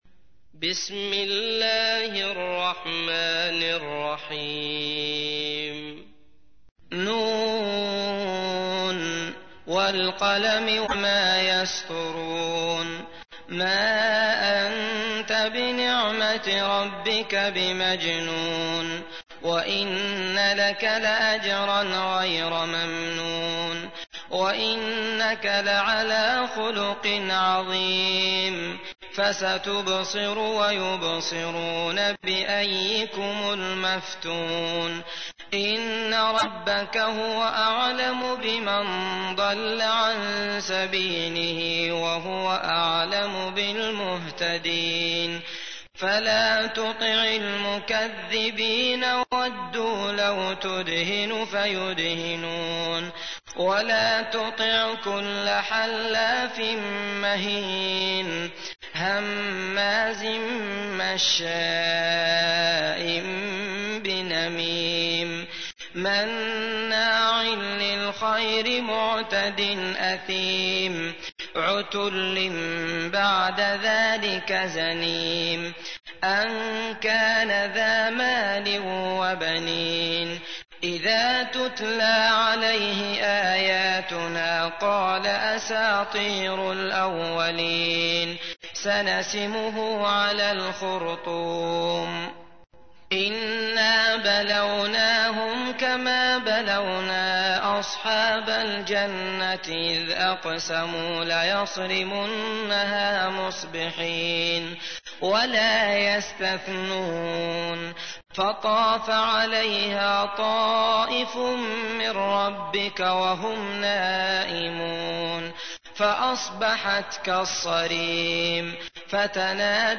تحميل : 68. سورة القلم / القارئ عبد الله المطرود / القرآن الكريم / موقع يا حسين